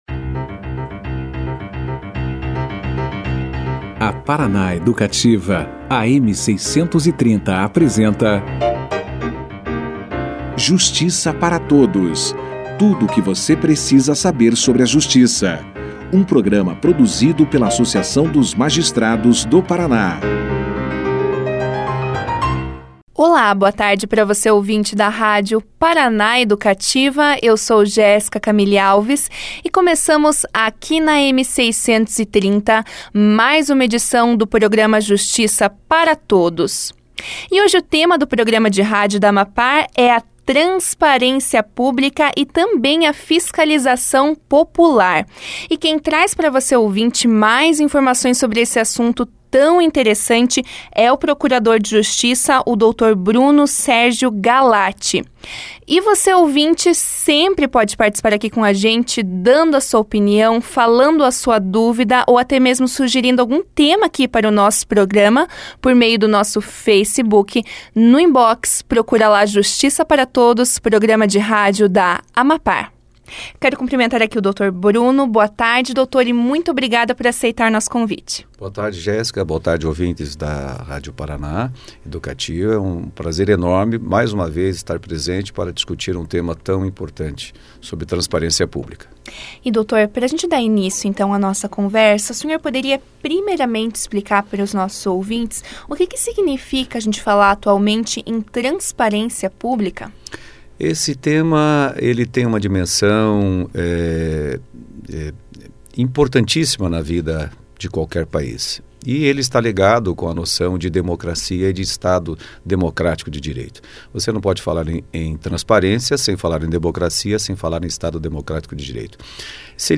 Na quinta-feira (21), o programa de rádio da AMAPAR, Justiça para Todos, conversou com o procurador de justiça Bruno Sérgio Galati sobre transparência pública e a fiscalização popular. O convidado deu início a entrevista explicando o que significa falar em transparência pública e sua importância para a democracia. Na oportunidade, o procurador também esclareceu como o cidadão pode ter acesso às informações públicas, destacou os efeitos positivos para a sociedade da fiscalização popular e apontou os resultados alcançados com a Lei da Transparência, ressaltando o desenvolvimento da cultura de participação social como ponto positivo da legislação.